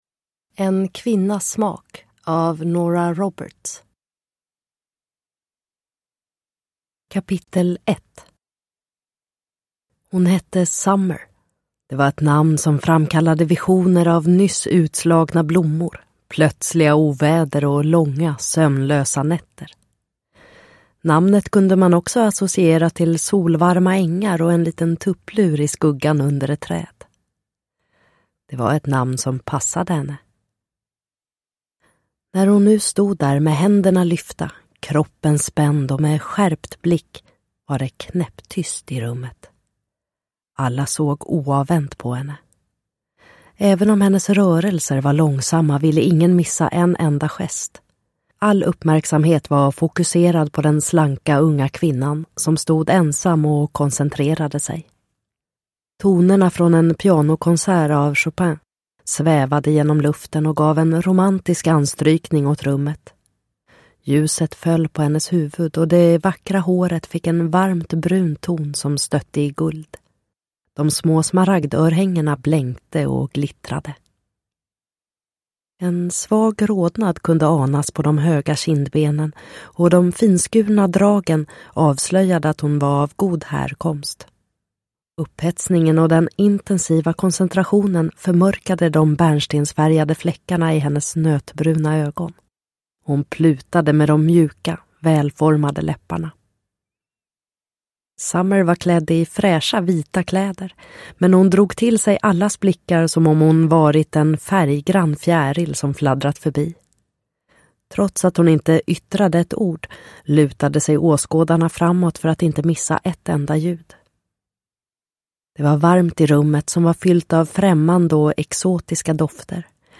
En kvinnas smak – Ljudbok – Laddas ner